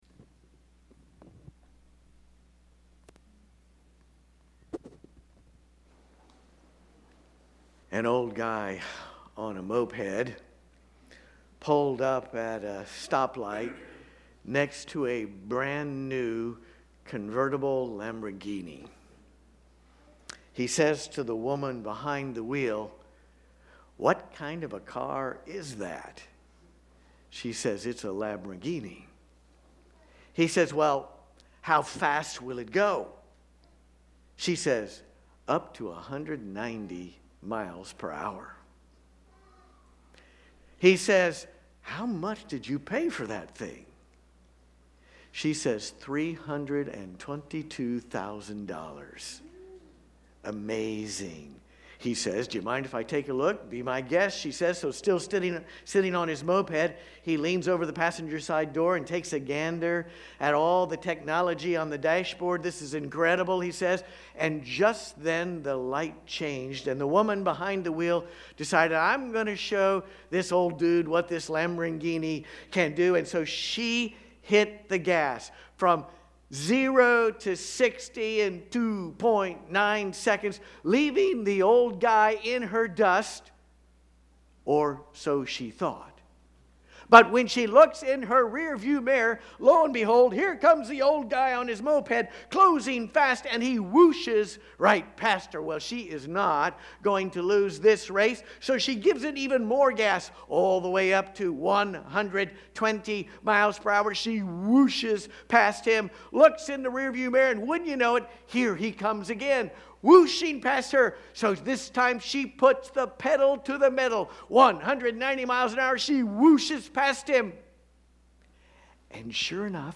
Watch the entire Worship Service
LIVE Stream Replay